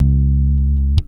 Snare_39.wav